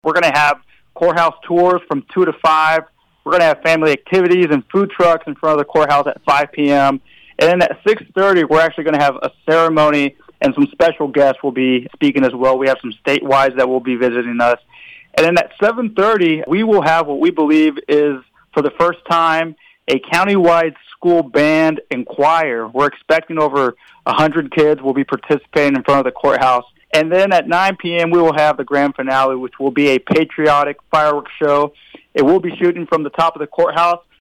Eastern District Commissioner Israel Baeza says several activities are planned for Saturday’s celebration, beginning at 2 p.m.